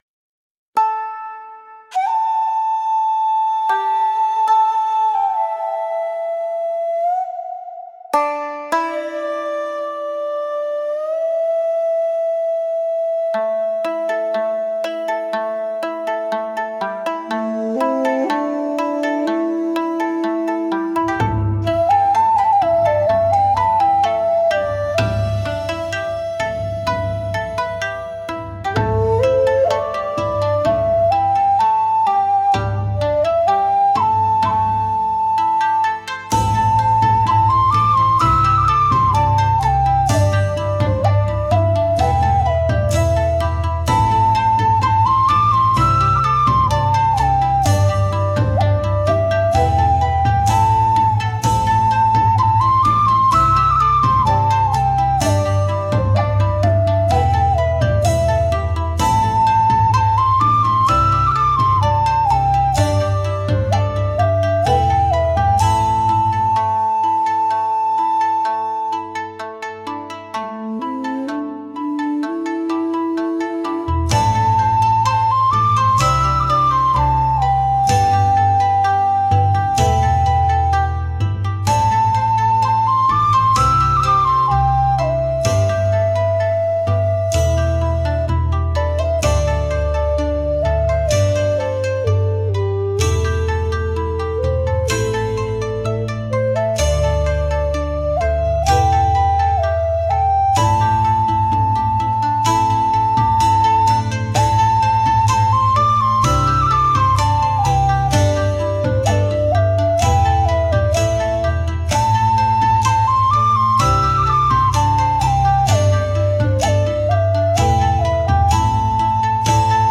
フリーBGM 🎶 琴や尺八の音が静かに響く、お正月らしい和風の穏やかなインストBGMです。